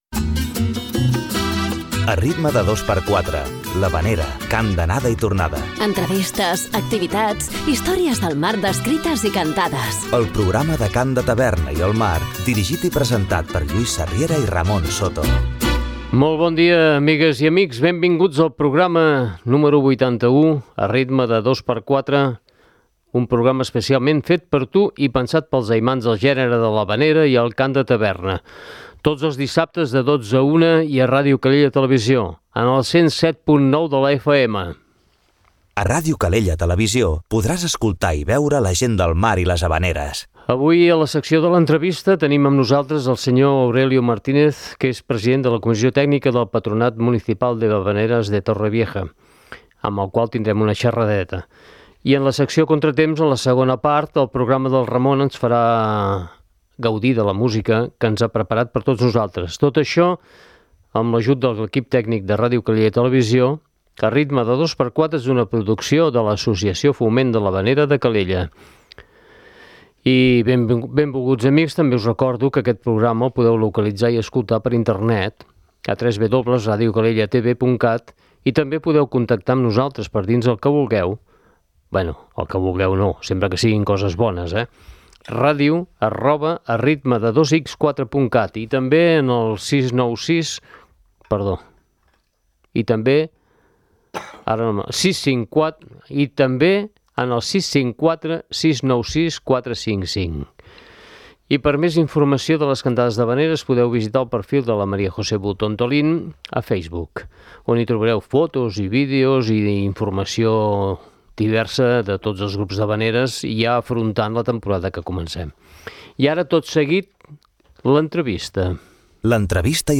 Avui a la secció de l’entrevista